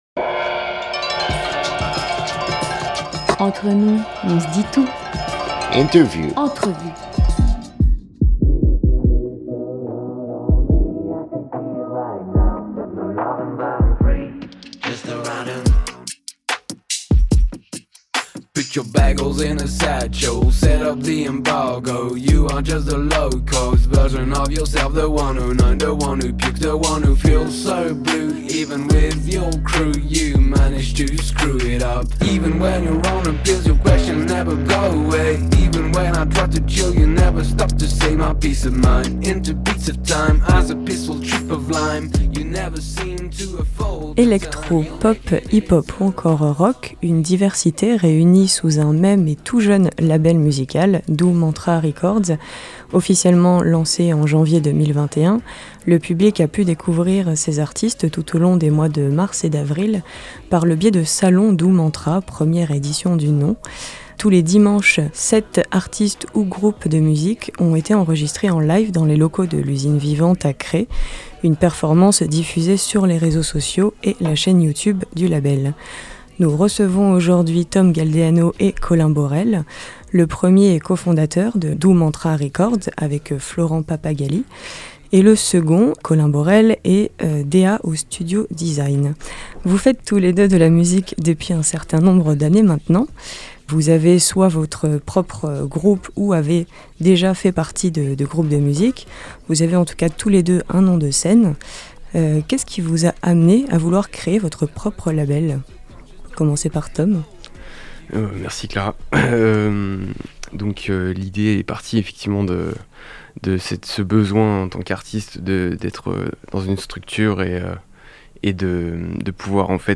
ITW-Label-Doux-Mantra_PAD.mp3